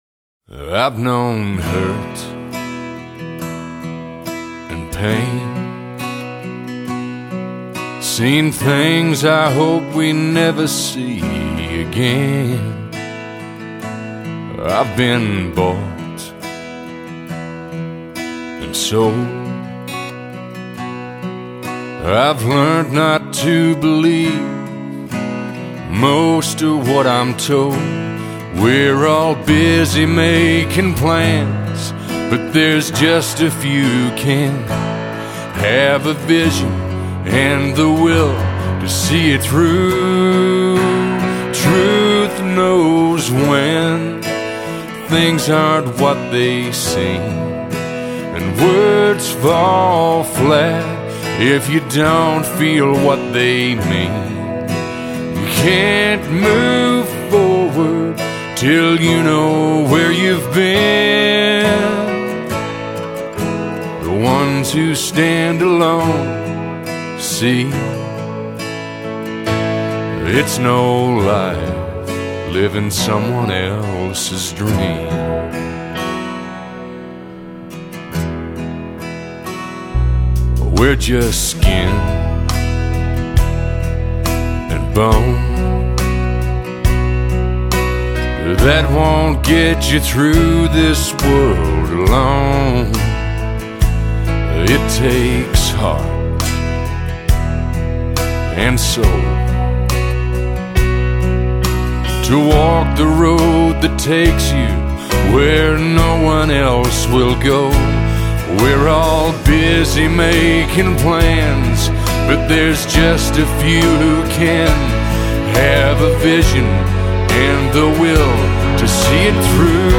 stirring ballad